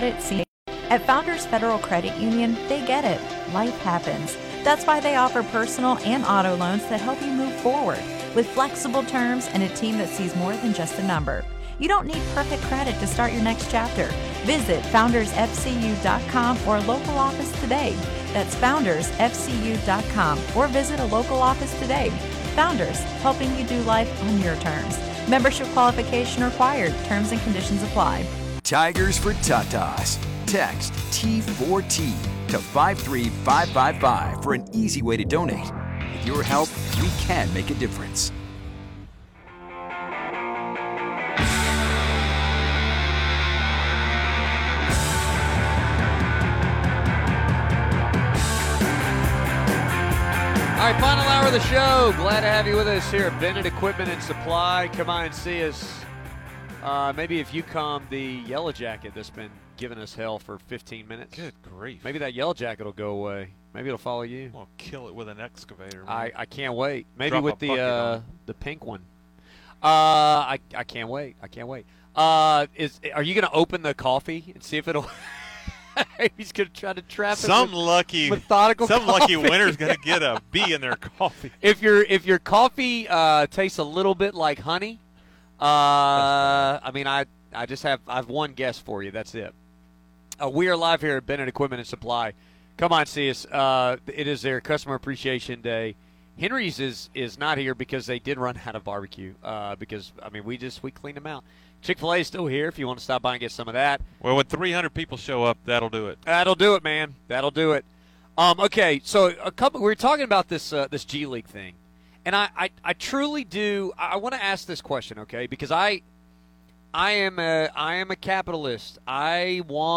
Out of Bounds is a fast paced show that covers all things sports both locally and nationally.